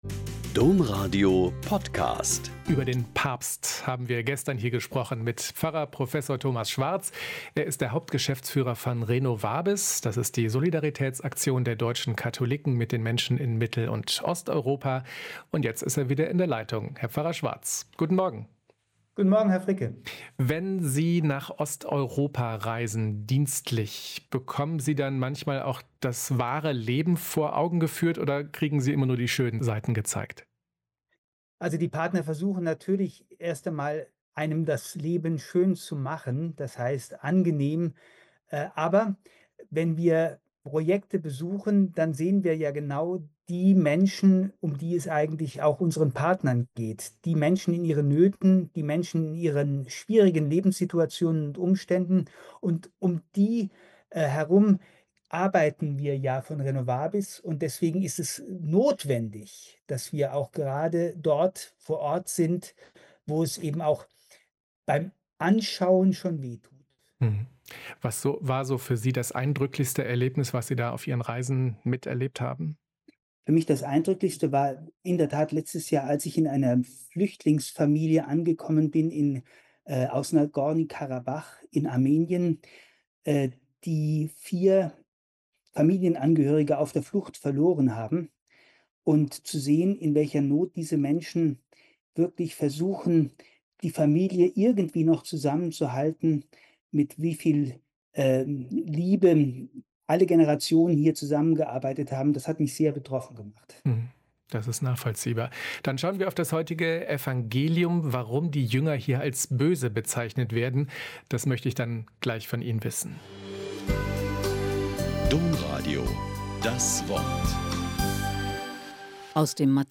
Mt 7,7-12 - Gespräch